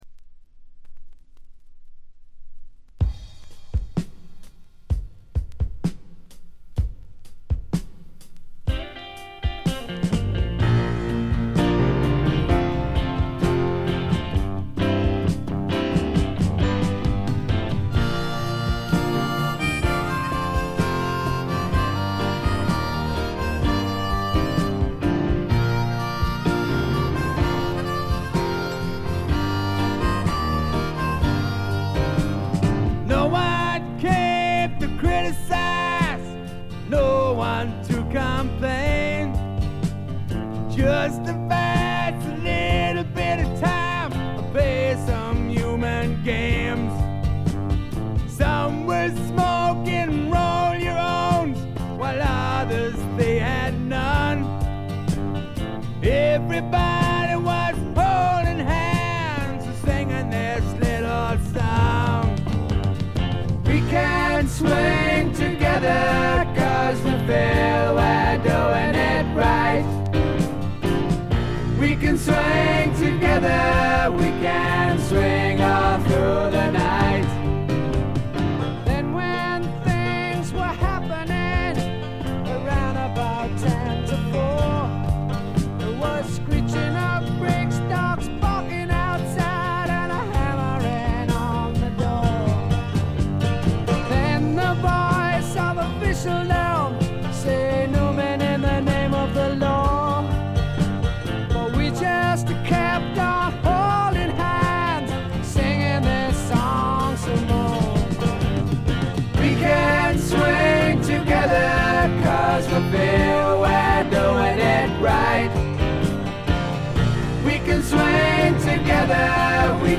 わずかなノイズ感のみ。
英国フォークロック基本！
試聴曲は現品からの取り込み音源です。